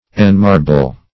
Search Result for " enmarble" : The Collaborative International Dictionary of English v.0.48: Enmarble \En*mar"ble\, v. t. [Pref. en- + marble.] To make hard as marble; to harden.